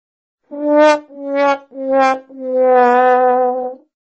Chiste malo cua cua cua cua
Tags: botonera de programa la nave invisible fatality